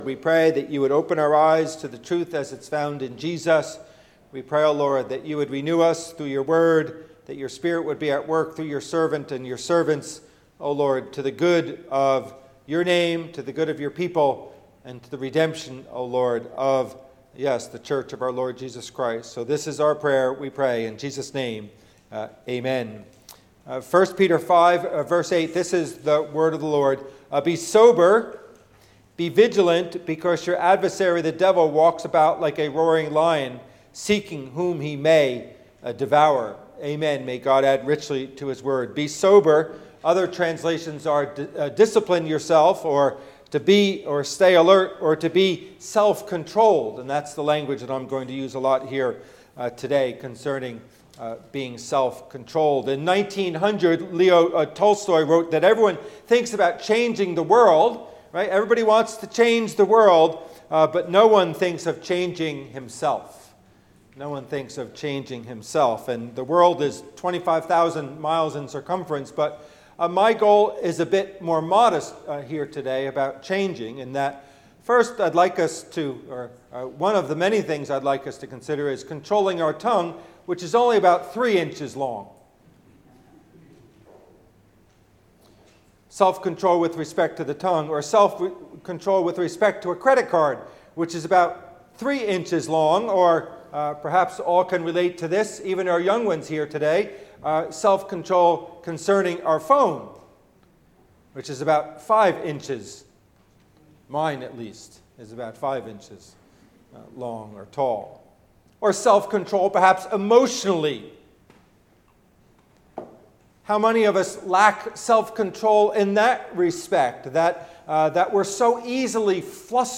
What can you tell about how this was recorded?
Passage: 1 Peter 5:8 Service Type: Worship Service